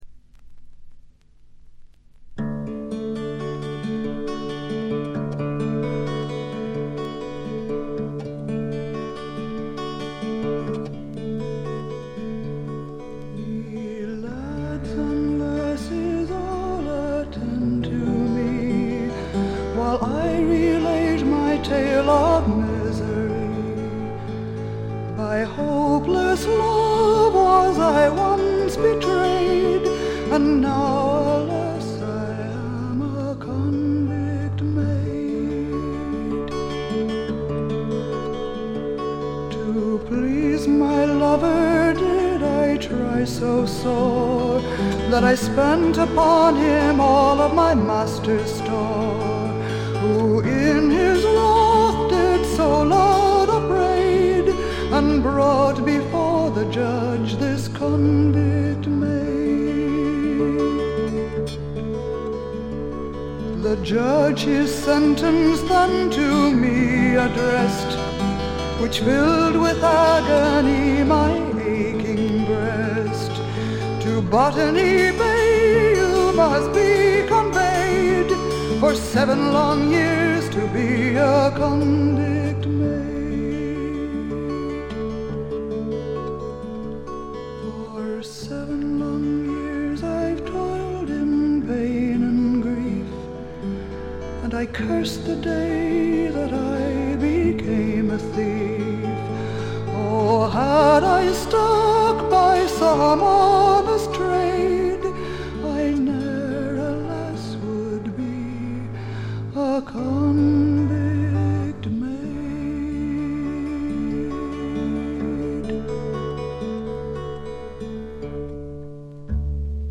実に魅力的なアルト・ヴォイスの持ち主で、初めて聴く方はまずはこの声にやられてしまうことでしょう。
この強力な声を武器にシンプルなバックを従えて、フォーキーでジャジーでアシッドでダークなフォークロックを展開しています。
試聴曲は現品からの取り込み音源です。
※後半でプツ音が2回出ますがこれが最も目立つノイズです。